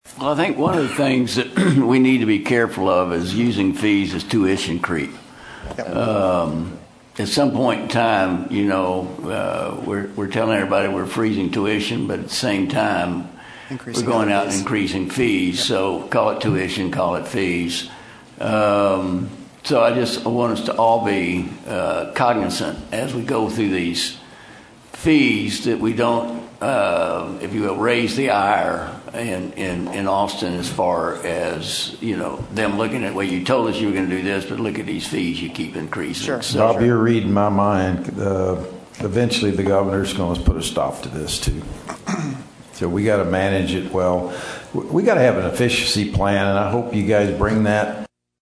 Click below to hear comments from Bob Albritton and John Bellinger during the February 5, 2026 board of regents finance committee meeting.